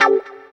136 GTR 2.wav